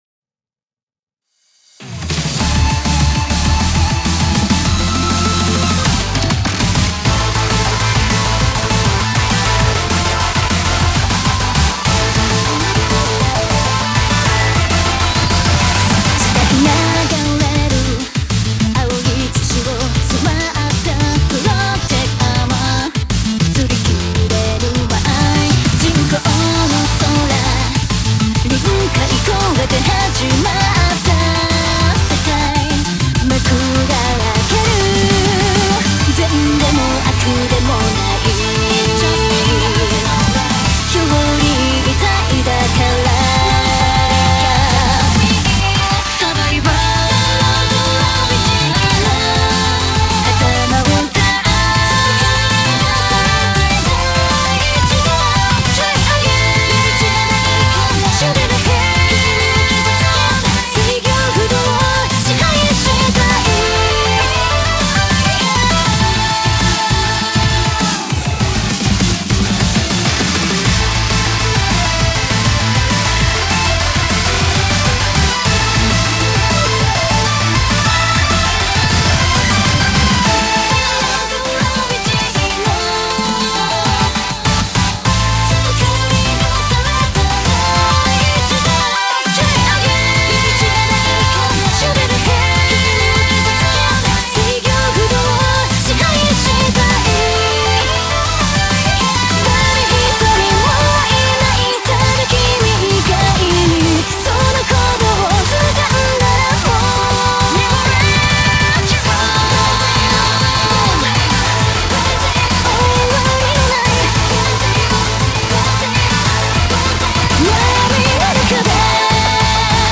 BPM200
Audio QualityPerfect (High Quality)